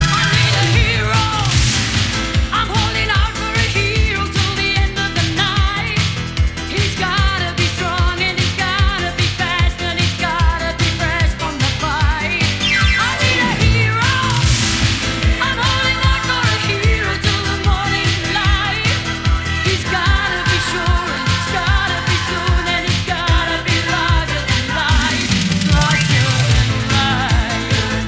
Shortened to just the first chorus